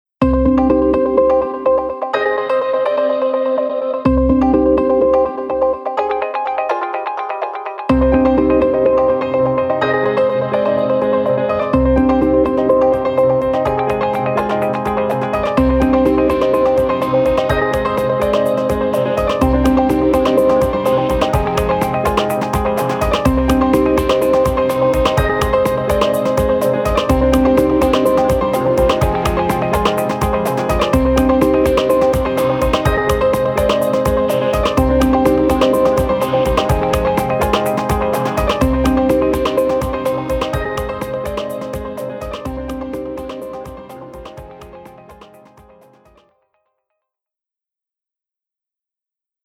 Electro & Urban